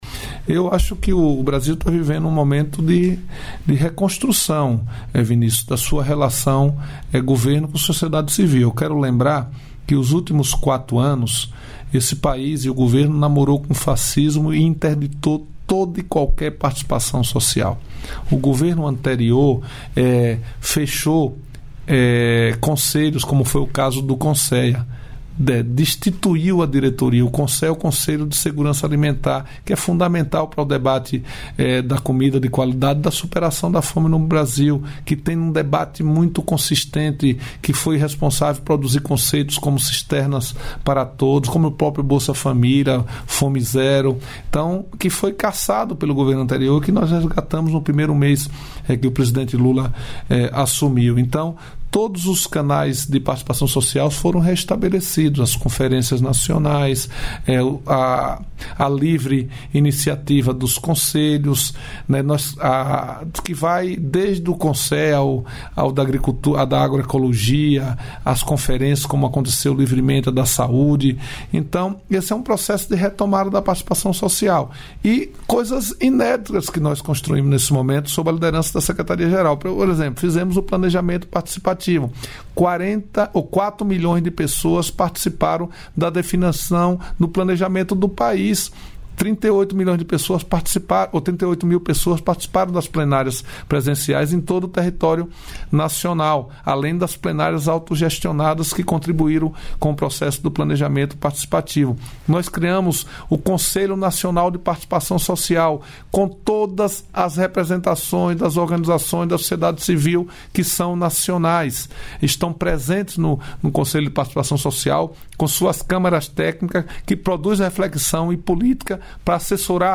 Trecho da participação do ministro-chefe da Secretaria-Geral da Presidência da República, Márcio Macêdo, no programa "Bom Dia, Ministro" desta quarta-feira (29), nos estúdios da EBC, em Brasília.